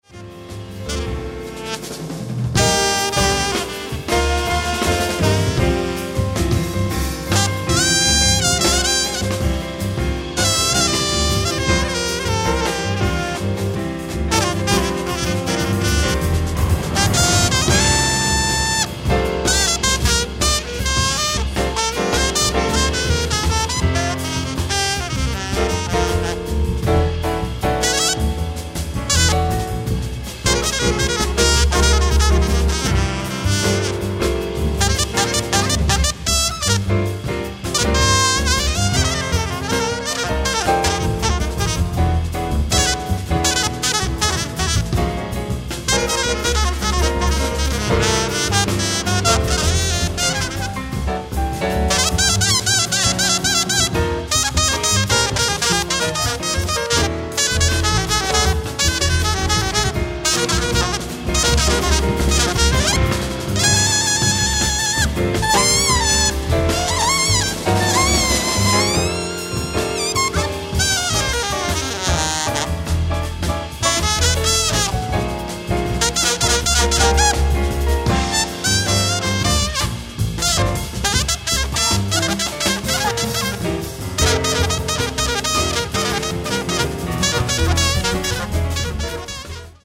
ライブ・アット・ゲスナーアレー、チューリッヒ、スイス 11/02/2016
※試聴用に実際より音質を落としています。